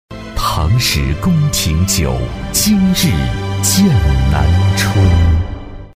男42-高端品质广告【剑南春（品质）】
男42-磁性质感 高端大气
男42-高端品质广告【剑南春（品质）】.mp3